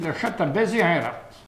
Patois - ambiance
Catégorie Locution